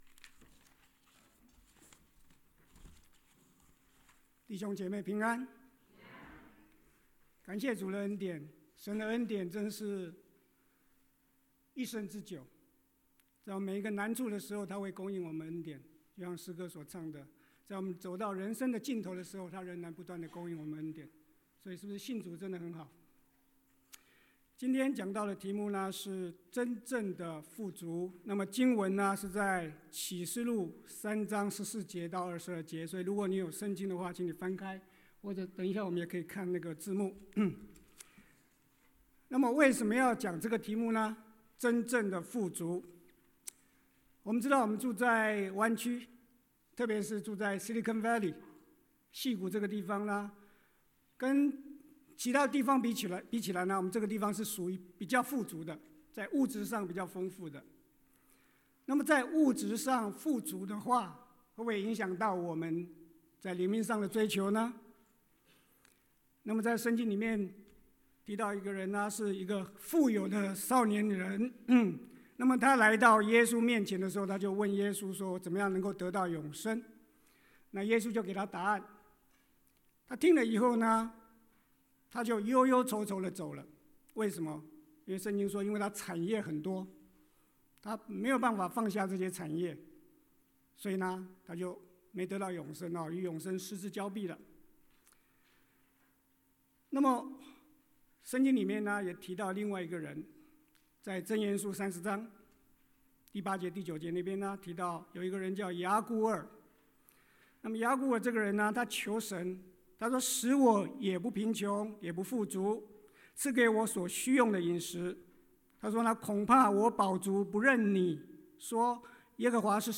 講道錄音 2019 – 恩信聖經教會